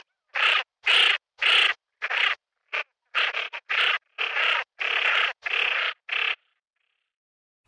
Sonidos y cantos de aves acuáticas.
Porrón común (Aythya ferina).
porron-comun.mp3